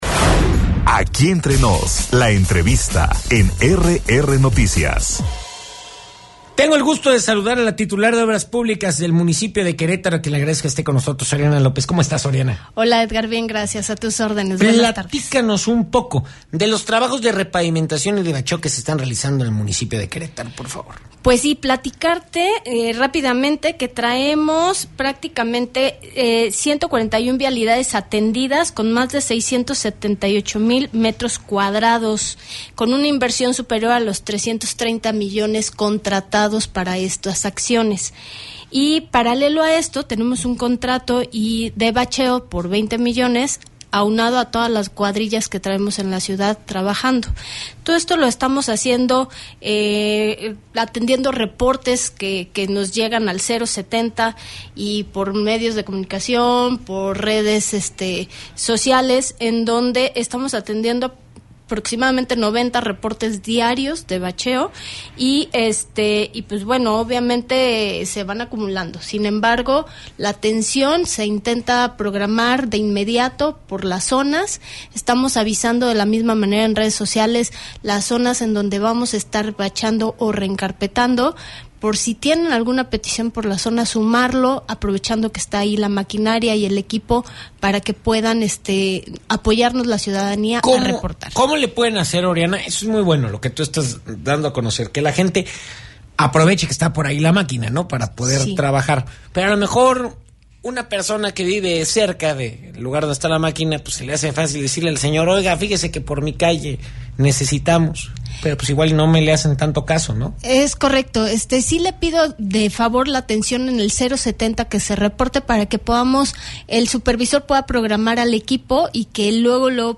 La entrevista con Oriana López Castillo, Secretaria de Obras Públicas del municipio de Querétaro
ENTREVISTA-ORIANA-LOPEZ.mp3